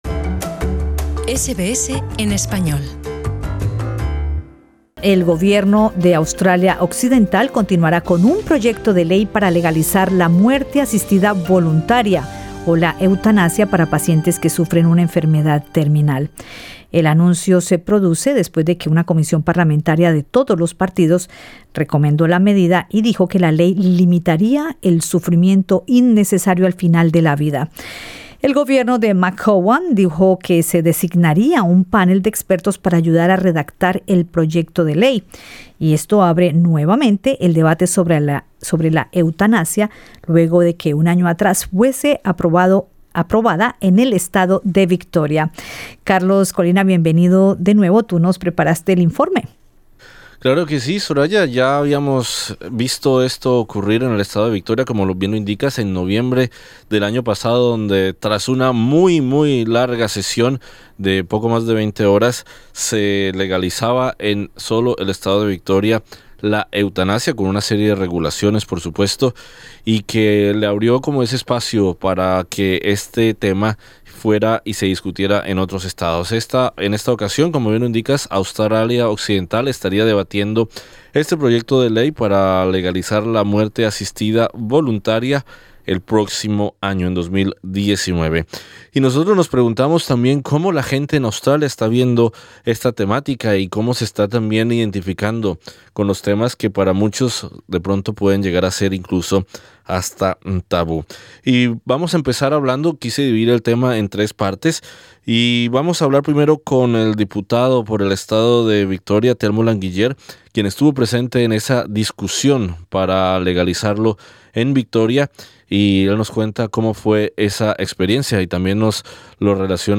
Escucha el informe con varios invitados y con opiniones de personas de la comunidad.